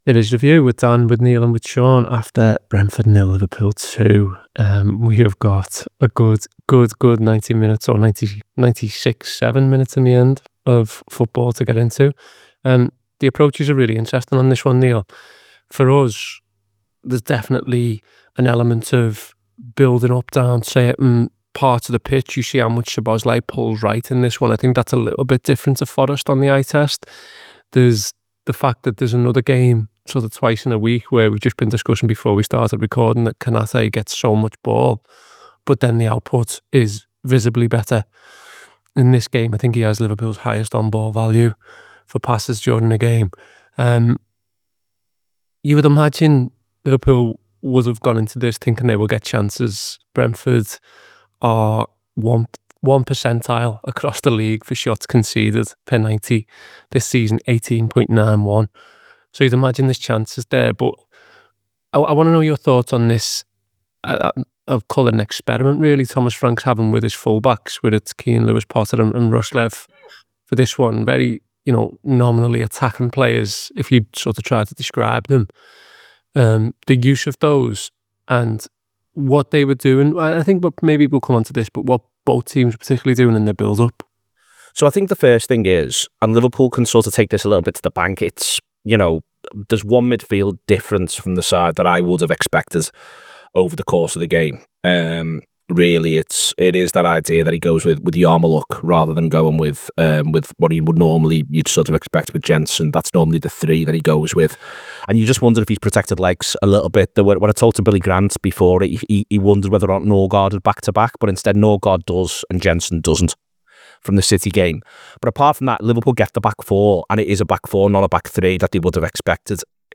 Below is a clip from the show- subscribe for more review chat around Brentford 0 Liverpool 2…